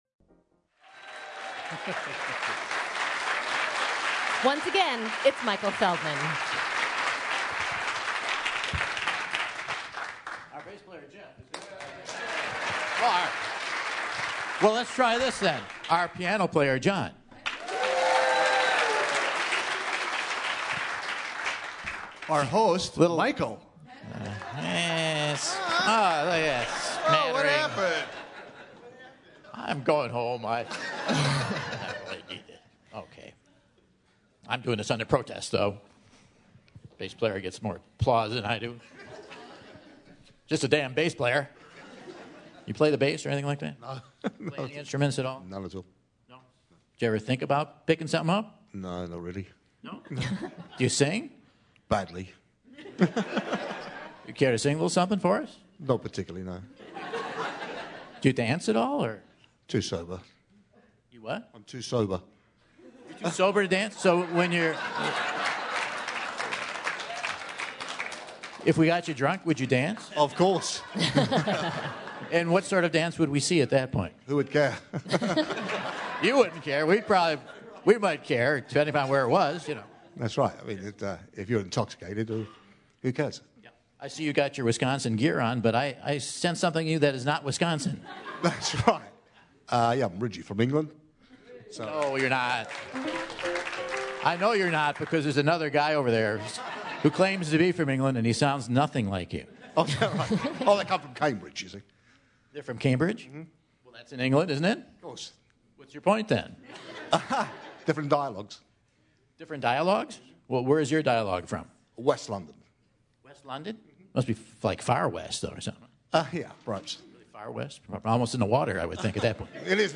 Before the quiz the bnad causes a ruckus